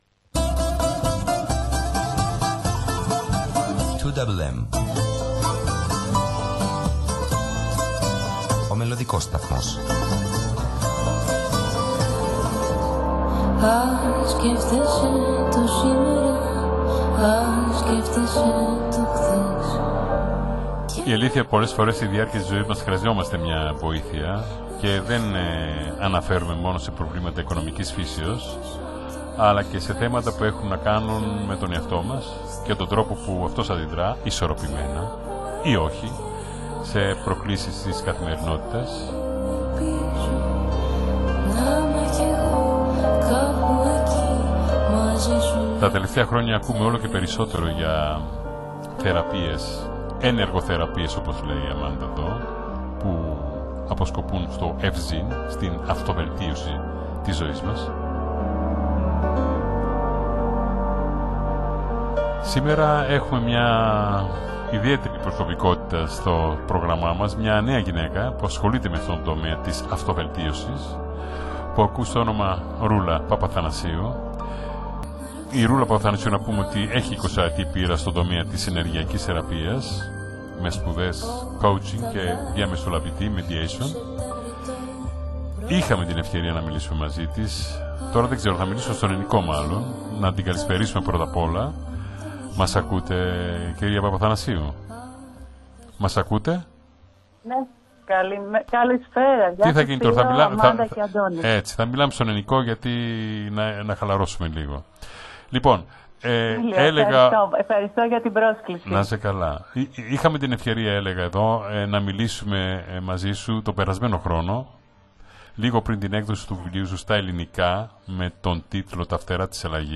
μίλησε σε ζωντανή σύνδεση